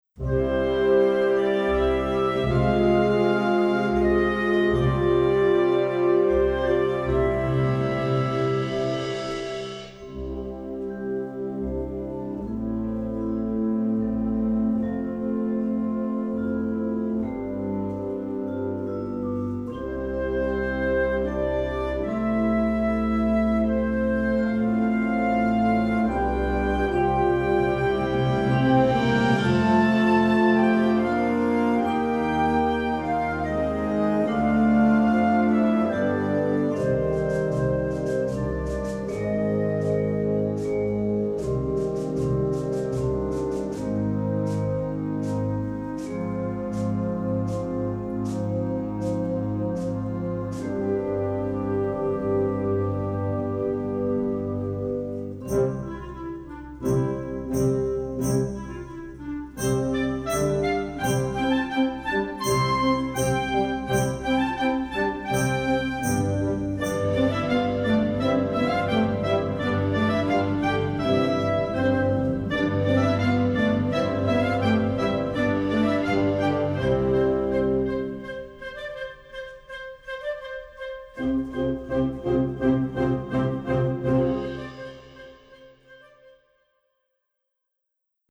Orchestre D'Harmonie